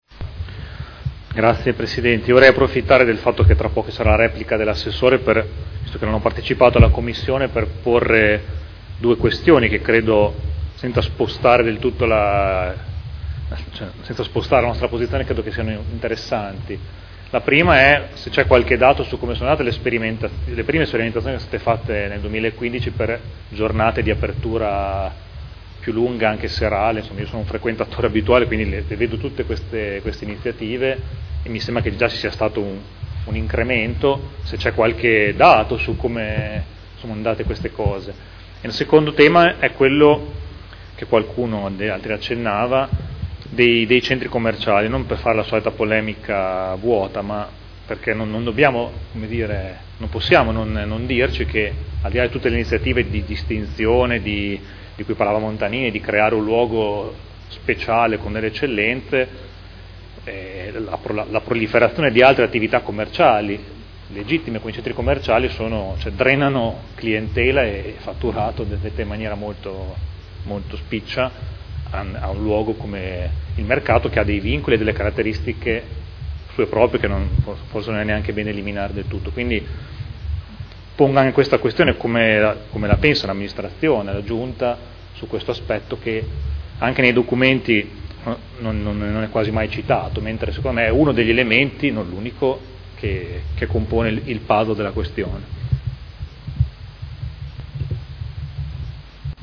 Seduta del 3 marzo. Proposta di deliberazione: Regolamento Comunale del Mercato coperto quotidiano di generi alimentari denominato “Mercato Albinelli”, ai sensi dell’art. 27, primo comma, lettera D. del D.lgs 114/98 – Approvazione modifiche. Dibattito